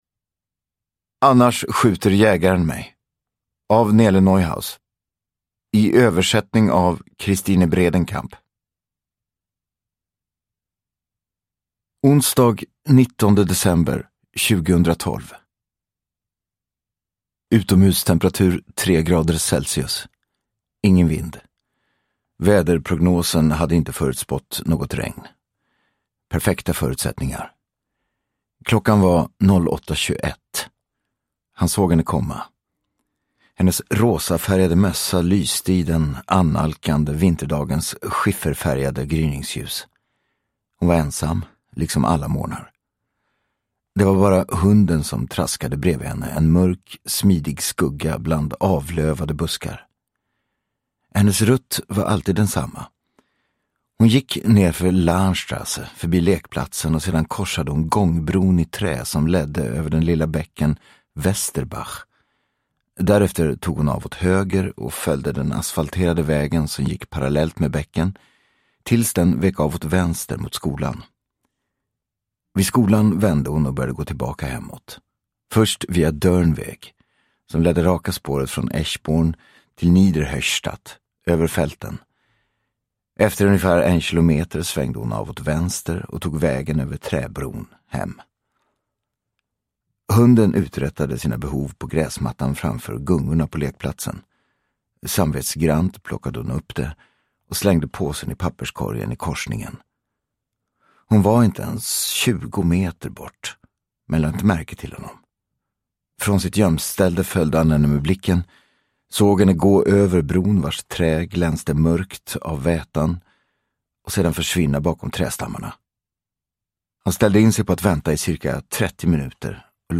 Annars skjuter jägaren mig – Ljudbok – Laddas ner